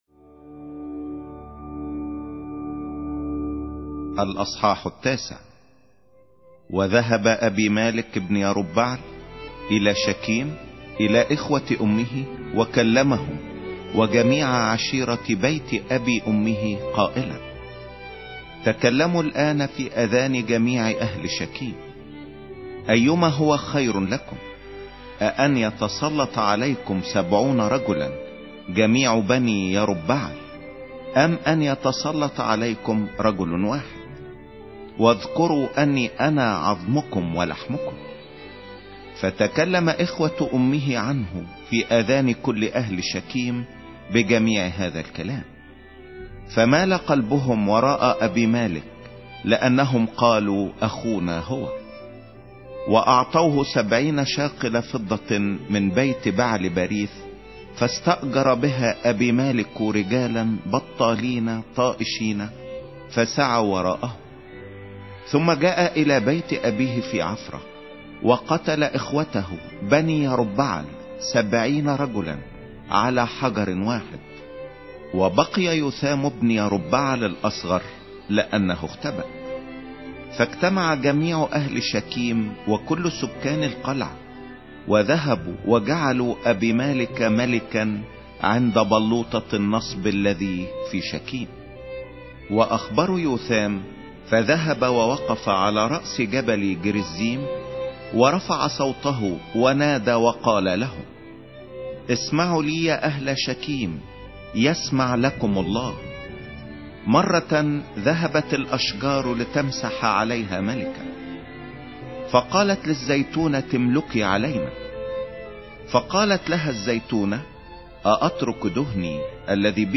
سفر القضاة 09 مسموع